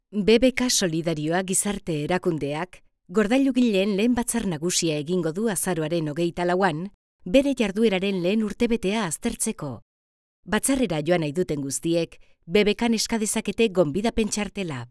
Locutora y actriz de doblaje.
Sprechprobe: Industrie (Muttersprache):
LOCUCION BBK EUSKARA [Alta calidad].mp3